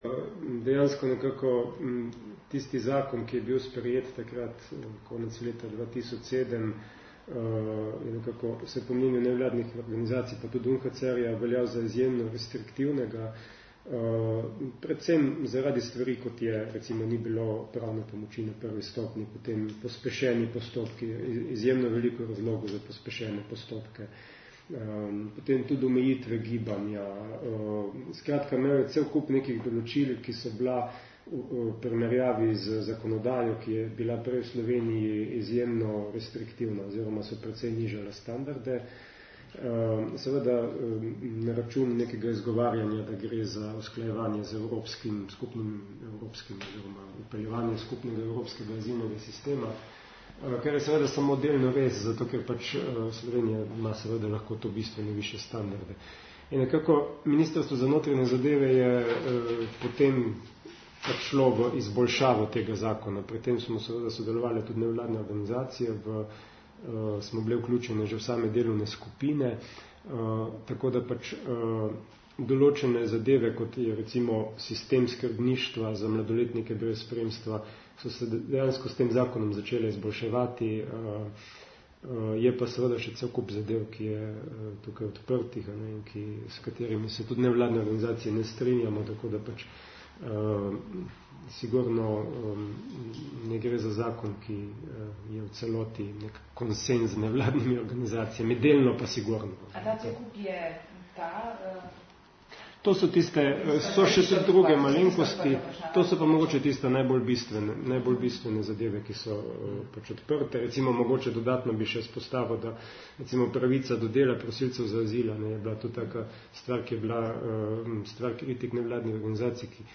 Zvočni posnetki izjave po srečanju (MP3)